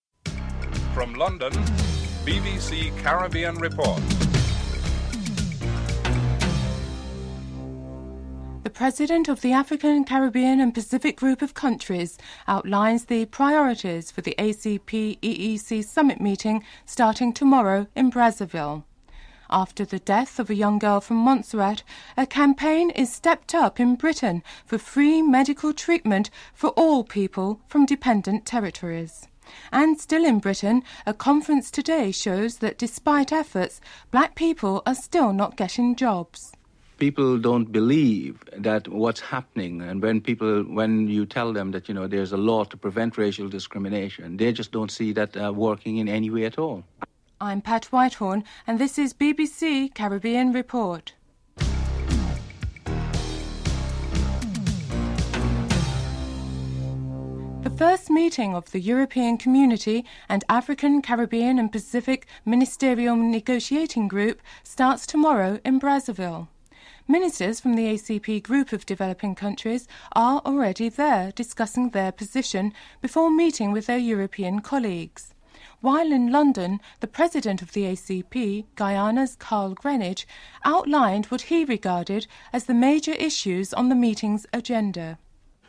Chris Patten, Minister for Overseas Development speaks on the need for greater liberalization of trade agreements, guarantees for traditional suppliers of goods like bananas, and the simplification of the rules of origin. The British Industrial Society conference on recruiting staff from ethnic minorities highlights discriminatory practices in the workplace.